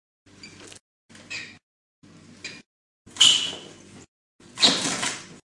Download Free Rat Sound Effects
Rat